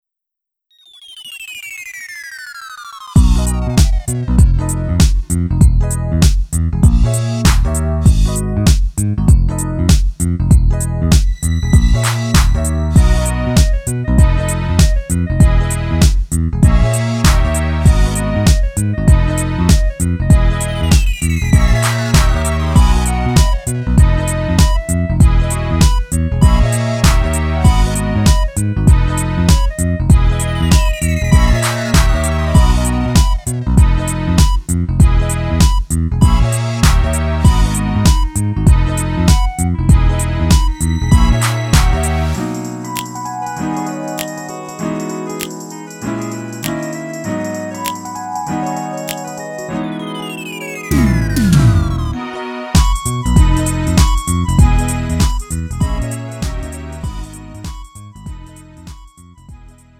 음정 -1키 3:43
장르 가요 구분